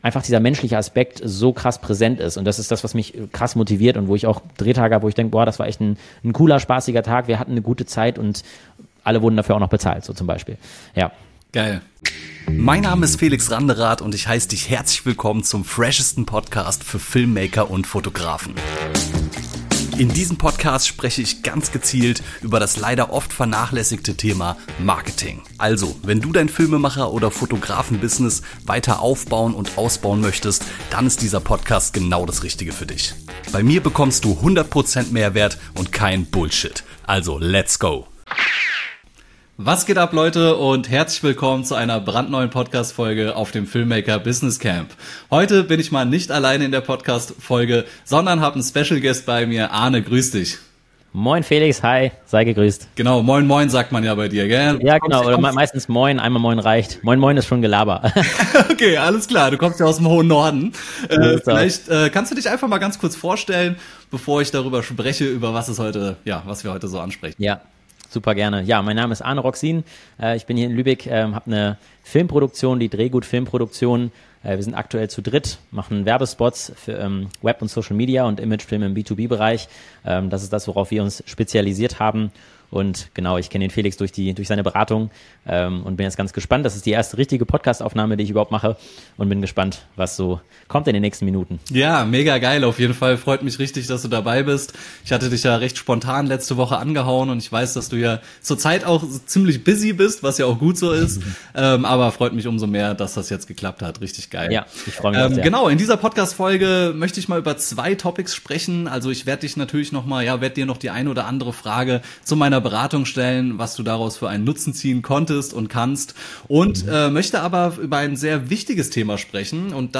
#051 Zwei Filmemacher fachsimpeln über Selbstdisziplin, Organisation und Motivation ~ Shootify Podcast